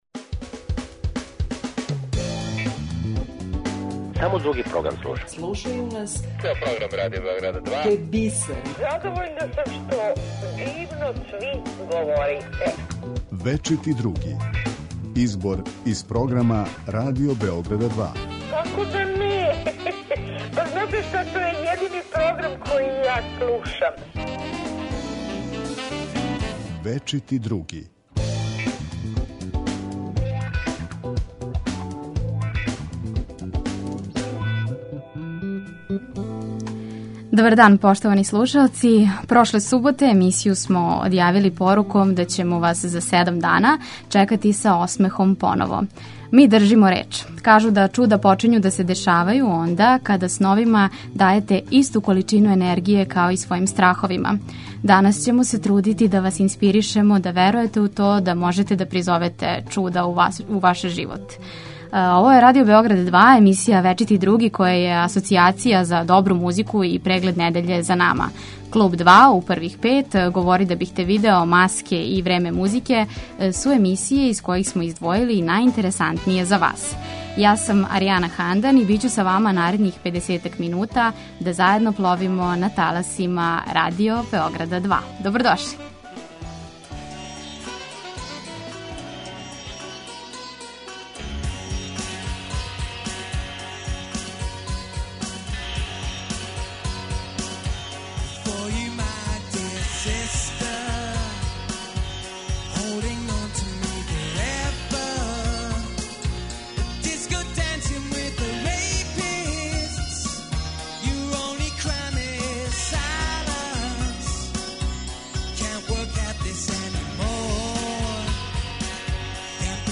Поред тога што издвајамо најбоље из протекле недеље, уживаћете у доброј музици и расположењу, на шта сте навикли суботом у 11 часова.